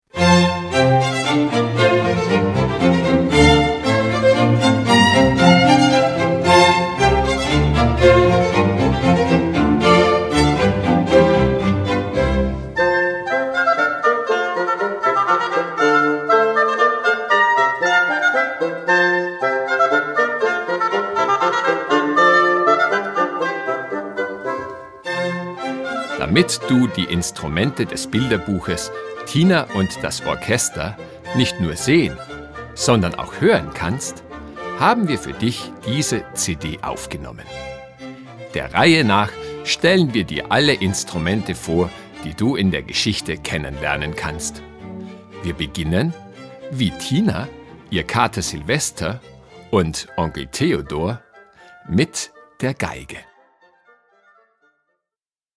Mit Tina, Onkel Theo und dem Kater Silvester lernen Kinder auf lustige, spielerische Weise die Instrumente des Orchesters kennen. Auf der dem Buch beigepackten CD wird jedes Instrument in Tonbeispielen und Begleittexten vorgestellt. Den Abschluss bildet ein Orchesterstück, aus dem man das Zusammenspiel der einzelnen Instrumente besonders gut erkennen kann.
Auf der Begleit-CD: zahlreiche Tonbeispiele und erklärende Texte zu jedem Instrument.